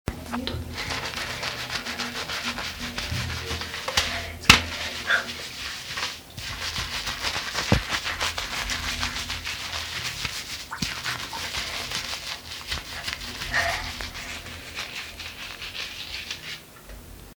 Lavando ropa a mano
Grabación sonora en la que se aprecia como alguien está lavando ropa a mano frotándola (se escucha el sonido del frote).
Sonidos: Acciones humanas